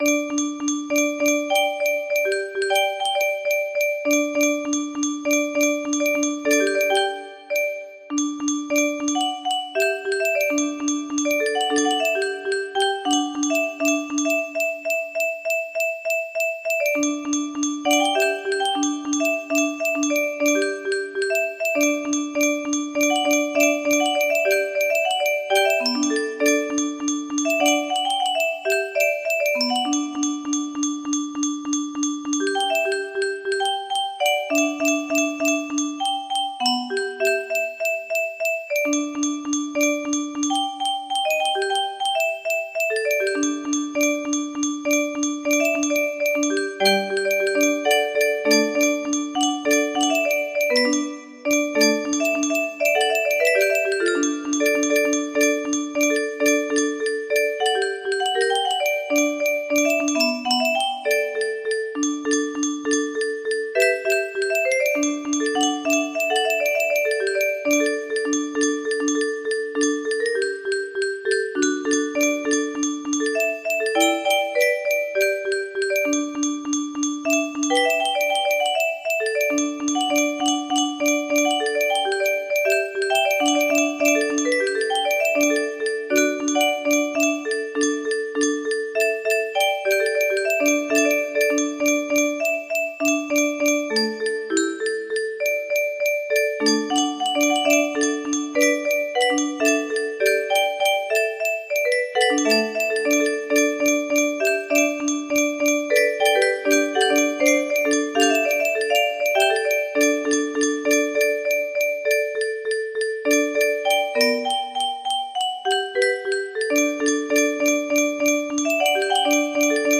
Unknown Artist - Untitled music box melody
Imported from MIDI from imported midi file (10).mid